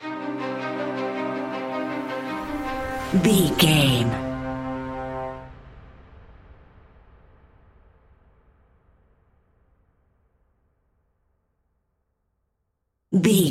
Piano and Strings Tension Music Theme Stinger.
Aeolian/Minor
ominous
suspense
eerie